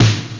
drum_kits
.1Snare_Dr.mp3